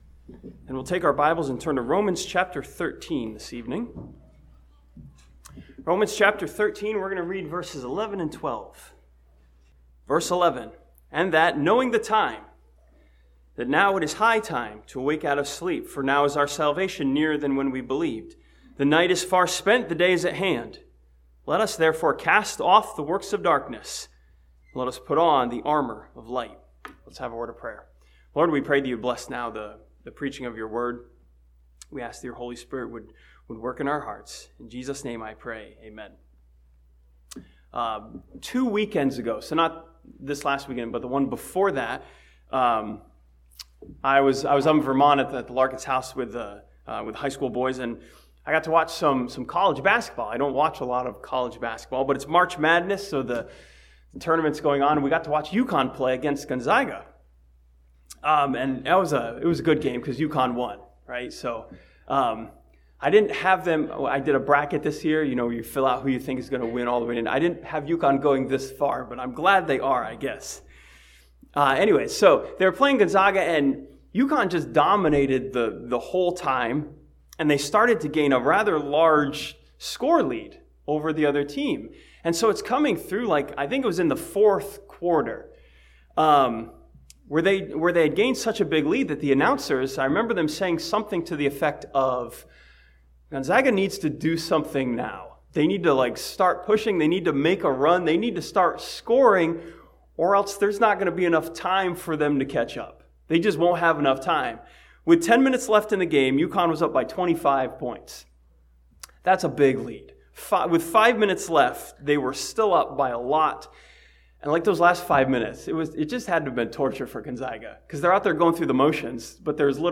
This sermon from Romans chapter 13 studies the message of this passage that it's time for believers to get to work.